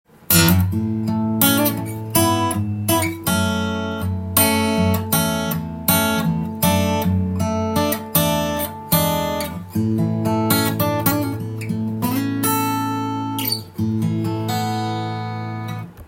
更に指弾きもしてみました。
そうです！マーチンD-28みたいな音です。マーチンよりも音が少し太い感じ。